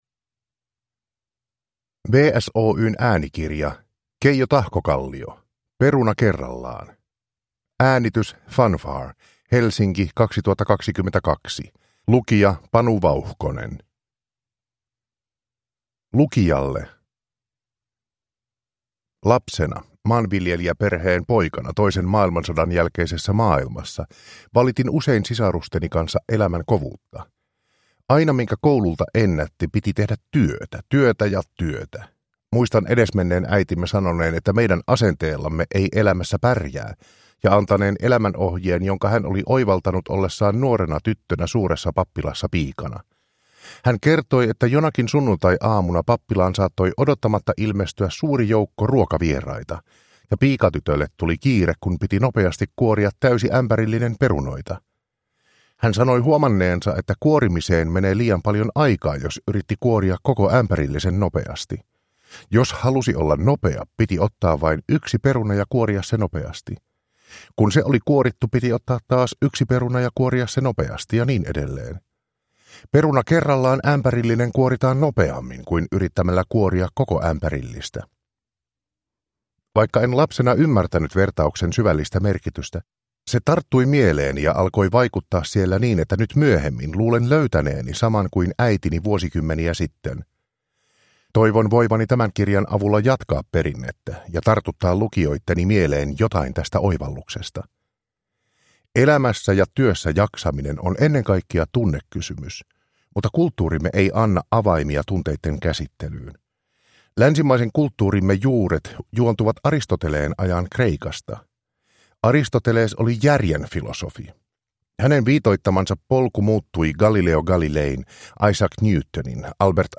Peruna kerrallaan – Ljudbok – Laddas ner